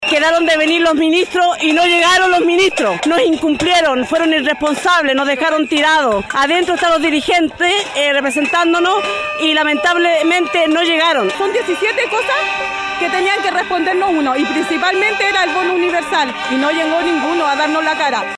En paralelo a esta reunión, damnificados realizaron una manifestación a las afueras del Congreso, instancia donde mostraron su molestia por la ausencia de las autoridades de Gobierno.